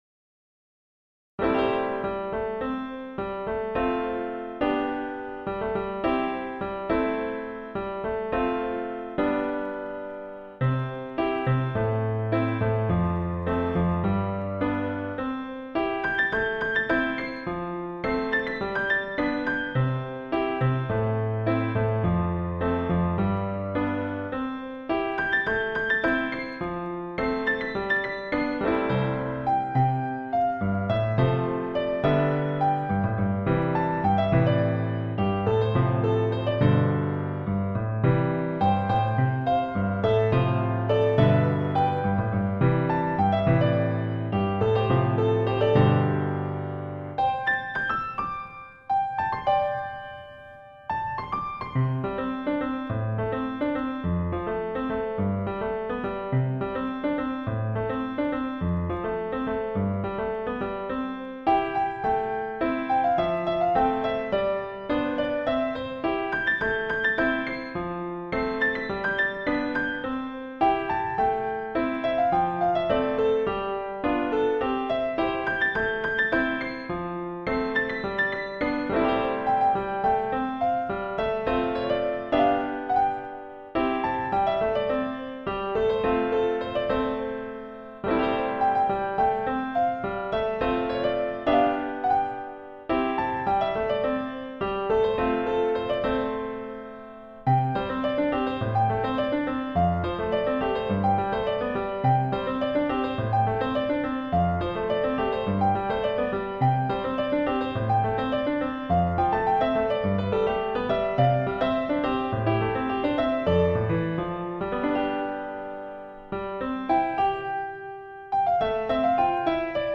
Ноты для фортепиано.
*.mp3 - аудио-файл для прослушивания нот.